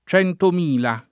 vai all'elenco alfabetico delle voci ingrandisci il carattere 100% rimpicciolisci il carattere stampa invia tramite posta elettronica codividi su Facebook centomila [ ©H ntom & la ] (ant. centomilia [ ©H ntom & l L a ]) num.